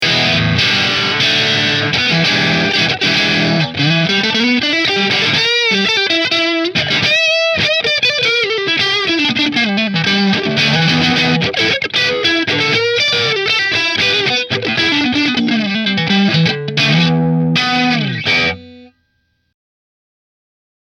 • HSS DiMarzio Pickup Configuration
B3 Metal XS Kerry Green Position 1 Through Marshall